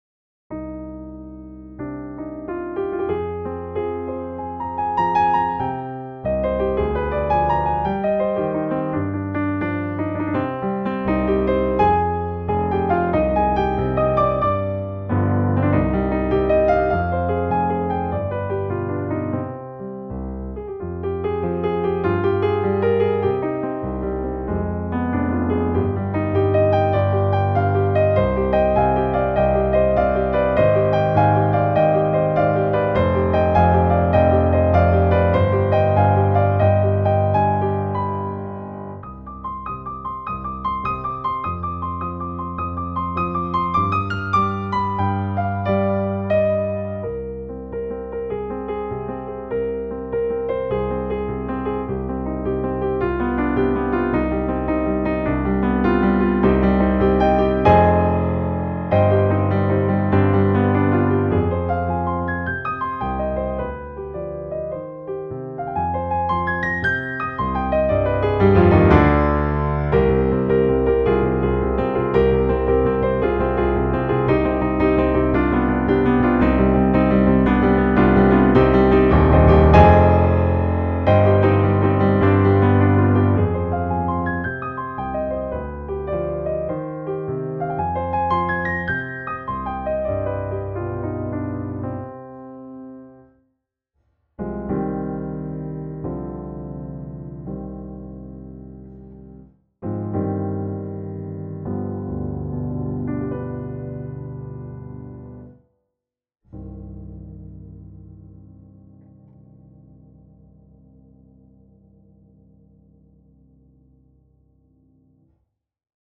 est une ballade, une invitation.
• Tonalité : Do mineur (avec modulations)
• Mesure(s) : 4/4 , 5/4 et 2/4